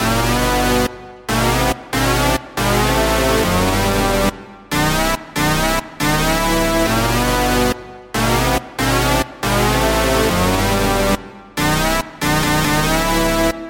未来贝斯 贝斯 1
标签： 140 bpm Trap Loops Bass Synth Loops 2.31 MB wav Key : Unknown
声道立体声